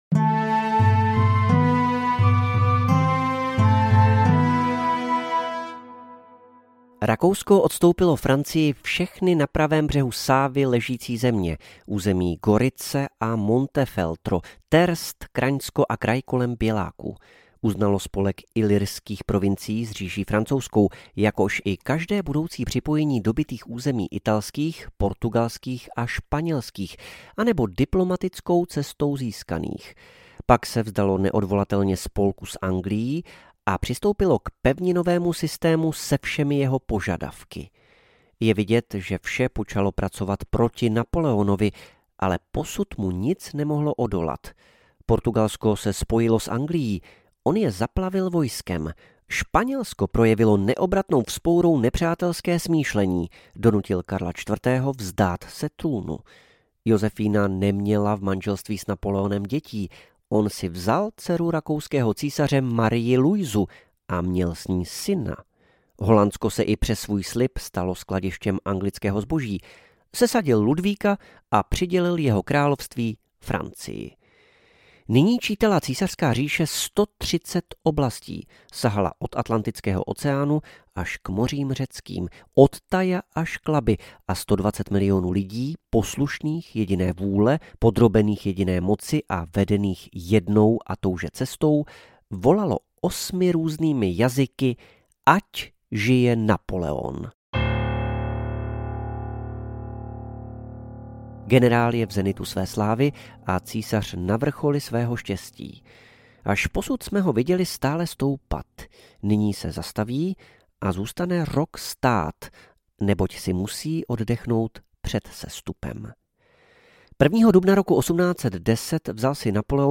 Napoleon audiokniha
Ukázka z knihy